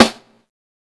SNARE_MIDDLE_FINGER_2.wav